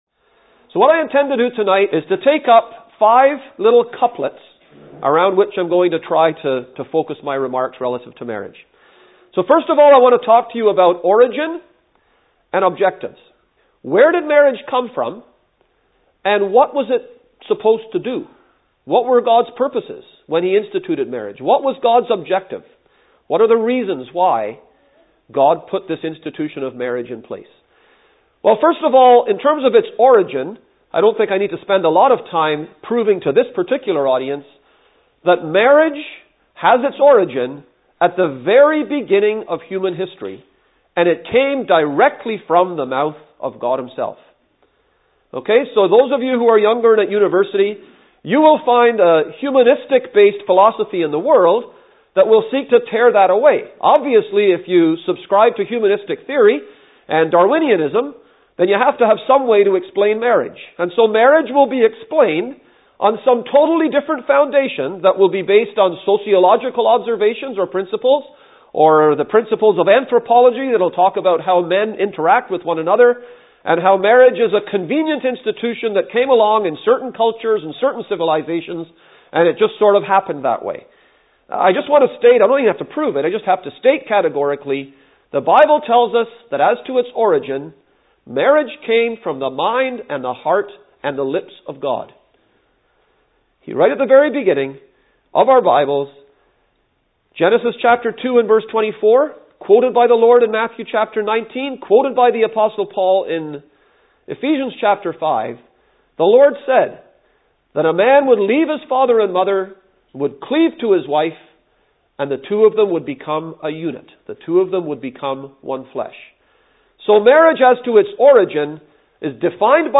Message preached 22nd Feb 2013